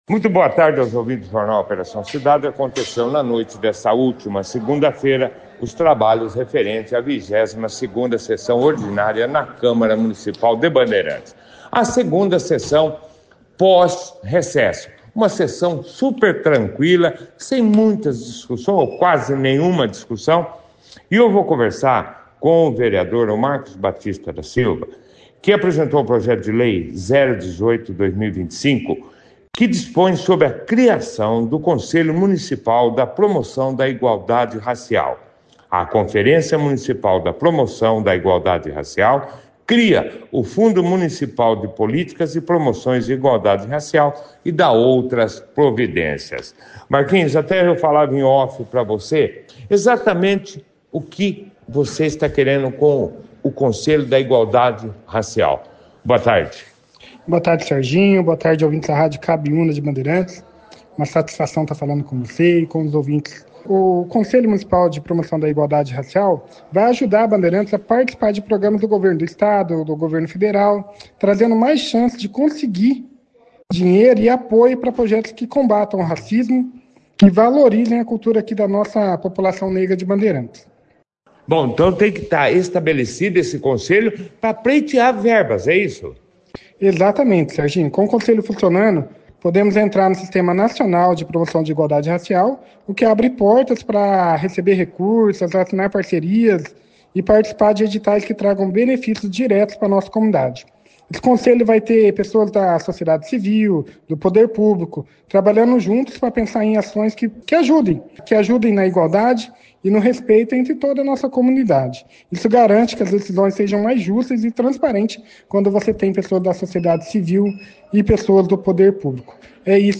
A sessão foi destaque na 2ª edição do Jornal Operação Cidade desta terça-feira, 12 de agosto, com a participação dos vereadores.